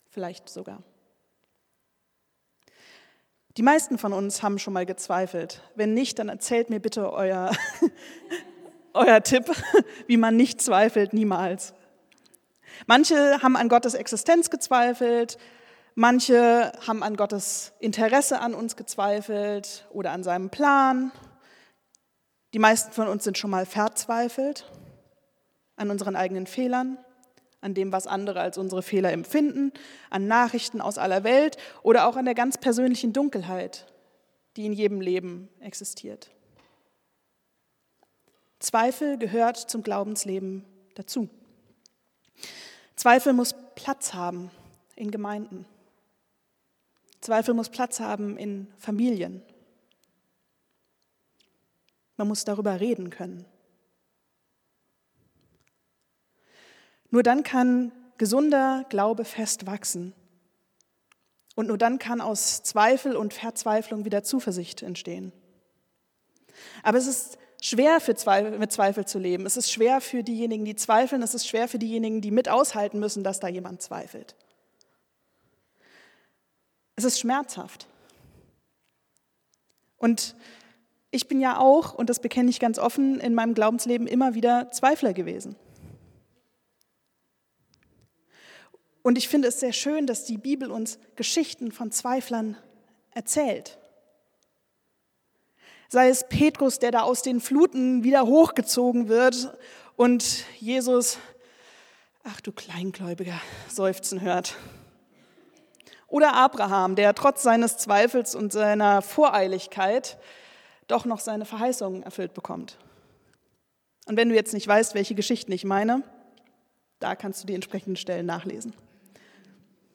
Predigt vom 01.03.2026